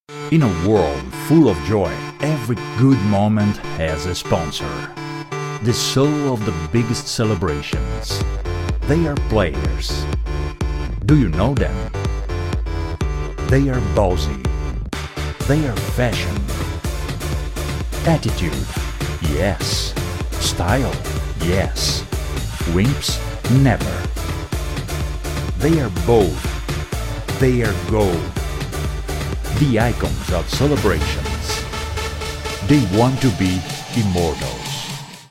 Locução em inglês para vídeo promocional Whisky.